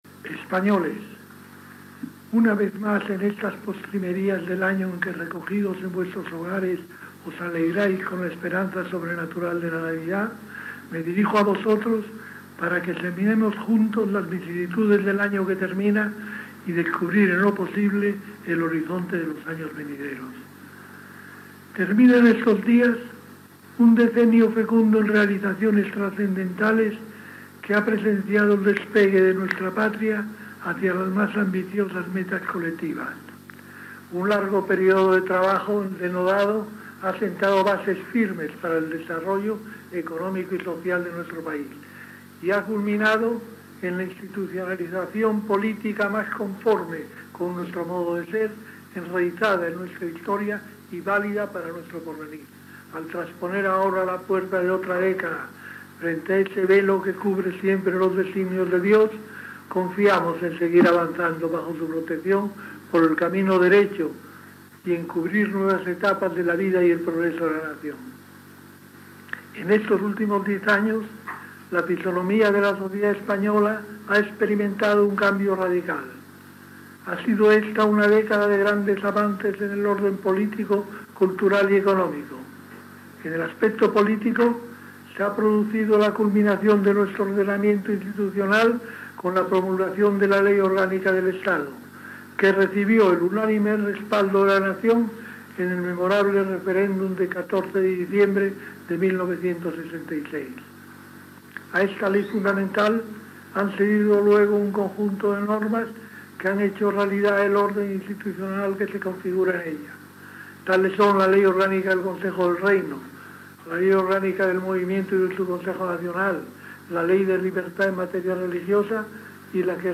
Discurso de Navidad del Jefe del Estado
Missatge de Nadal del cap d'Estat "Generalísimo" Francisco Franco en el qual valora l'any i la dècada i expressa que quan acabi el seu mandat tot restarà "atado, y bien atado"